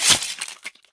combat / weapons / default_swingable / flesh2.ogg
flesh2.ogg